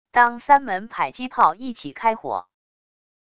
当三门迫击炮一起开火wav音频